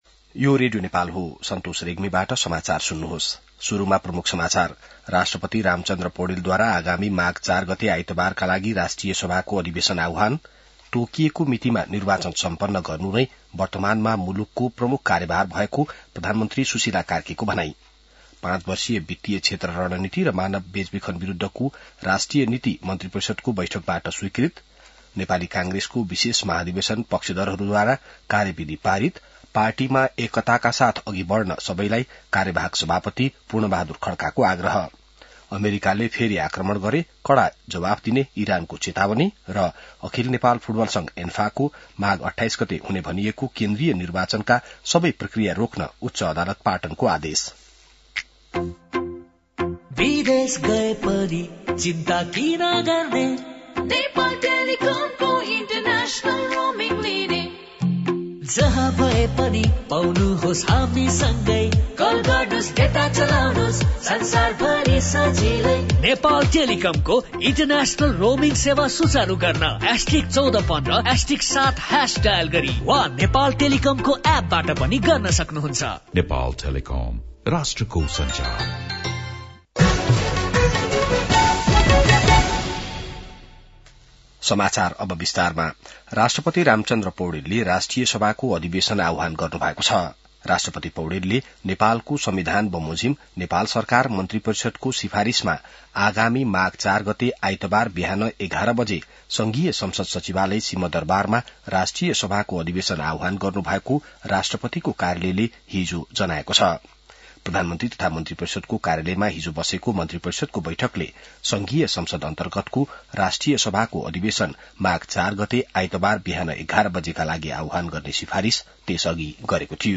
An online outlet of Nepal's national radio broadcaster
बिहान ७ बजेको नेपाली समाचार : २९ पुष , २०८२